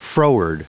Prononciation du mot froward en anglais (fichier audio)
Prononciation du mot : froward